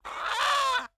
duck_01.wav